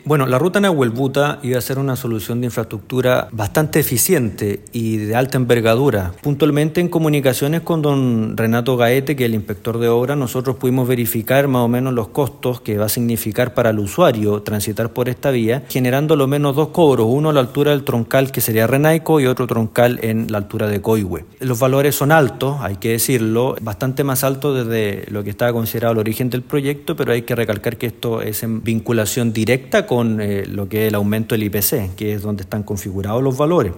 Frente a esta situación, el concejal de Angol, Diego Beltrán, se refirió al cobro de los peajes.